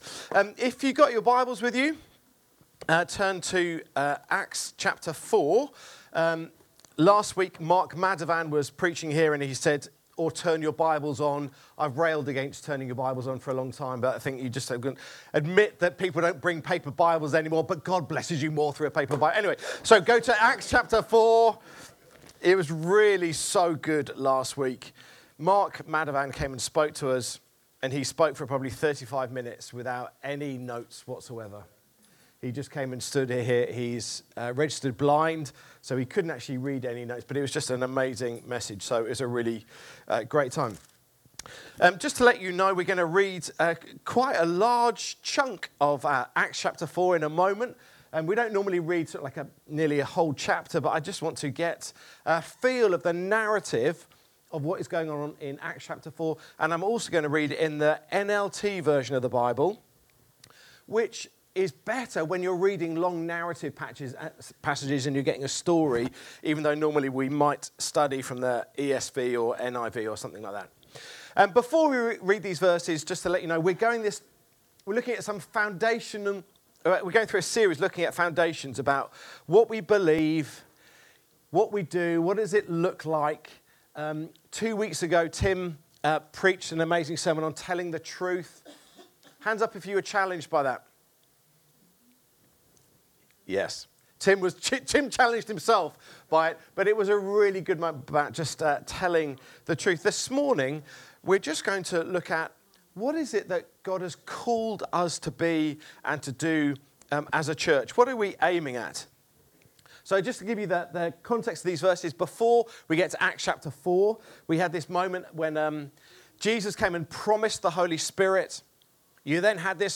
Download Disciples that make disciples | Sermons at Trinity Church